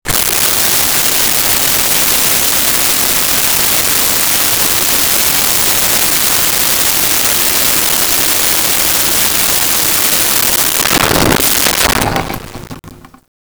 Bathroom Faucet 1
bathroom-faucet-1.wav